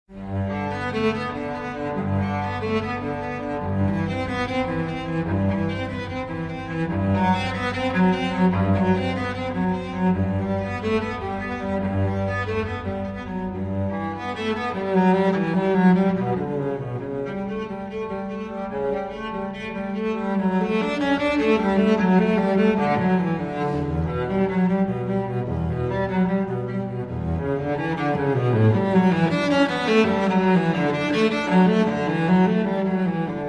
Strings 6